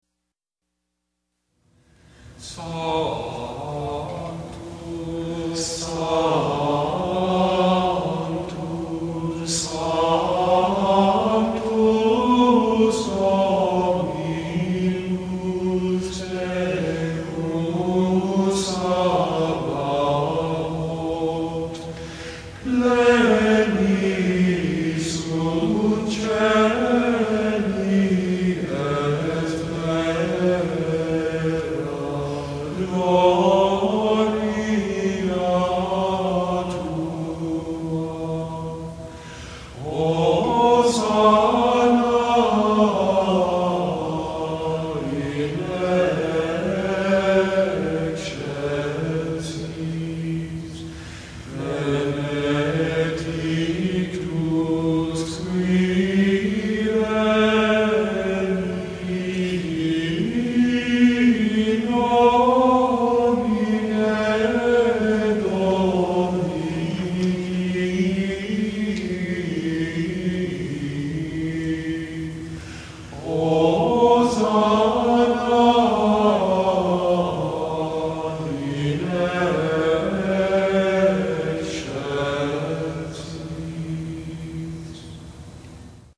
Lateinische-Messe-32-AdoremusHymnal_124_sanctus.mp3